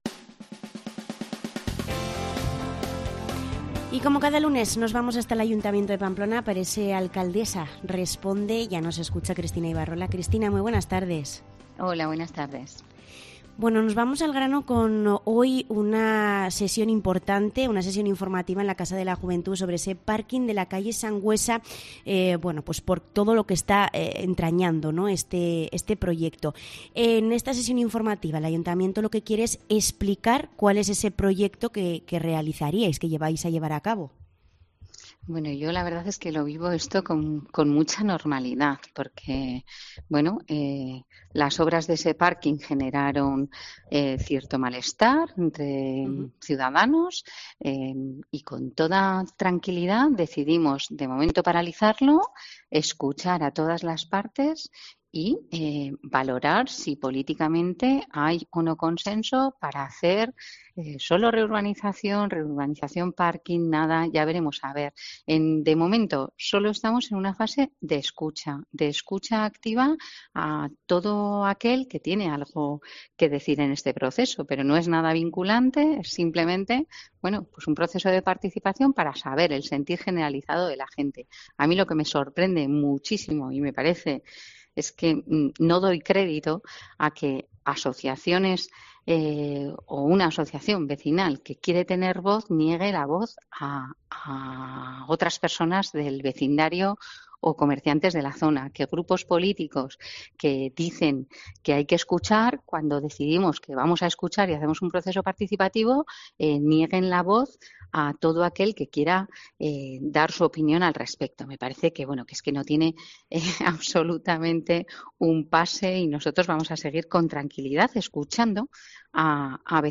Cristina Ibarrola desvela en Cope dónde estará ubicado el nuevo albergue para personas sin hogar
La alcaldesa de Pamplona, Cristina Ibarrola, ha anunciado hoy en Cope Navarra que el nuevo albergue para personas sin hogar estará ubicado en el edificio de las Damas Apostólicas del Corazón de Jesús, en la avenida de Galicia de la capital navarra. Un albergue que se abrirá previsiblemente este próximo viernes y que tendrá capacidad para 50 personas.